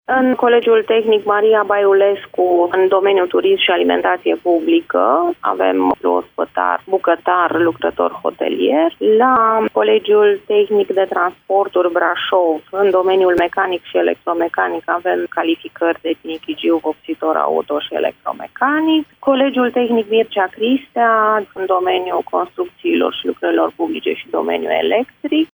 Inspectorul şef al Inspectoratului Şcolar Judeţean Braşov, Ariana Bucur.